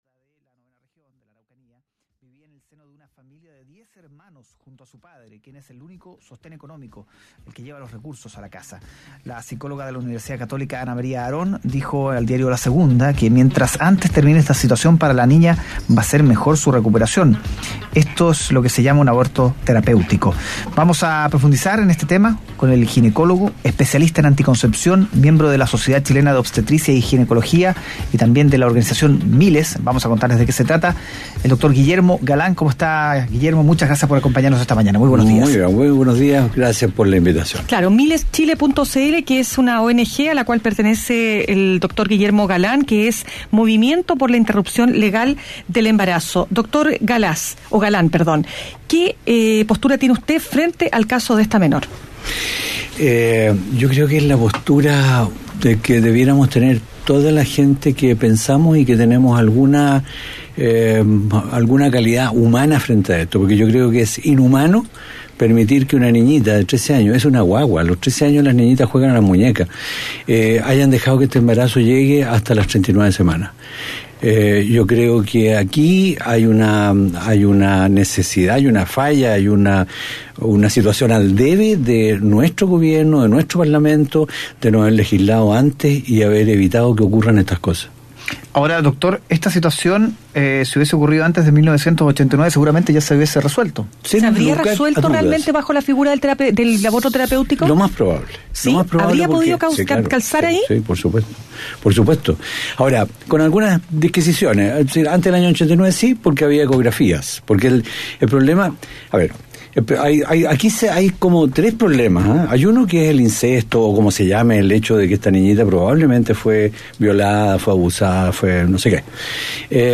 Escucha la entrevista completa en Mañana Será Otro Día: